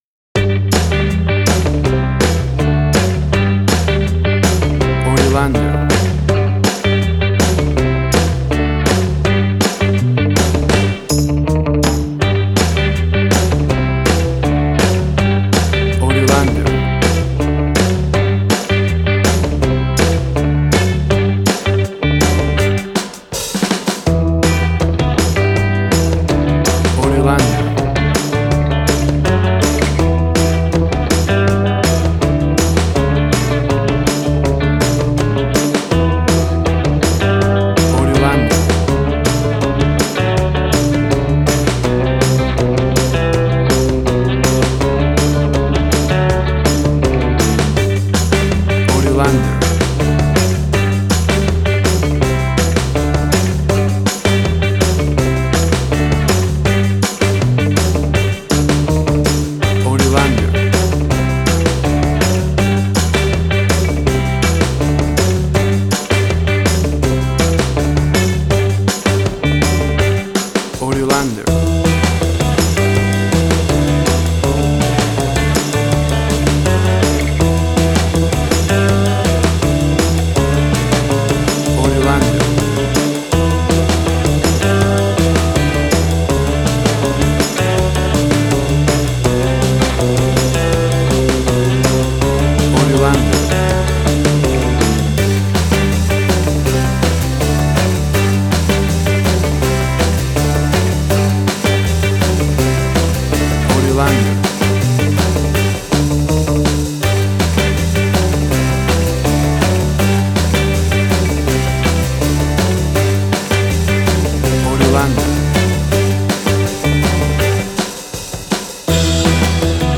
Tempo (BPM): 81